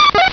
Cri de Coxy dans Pokémon Rubis et Saphir.